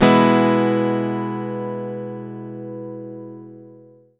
OVATION E-DU.wav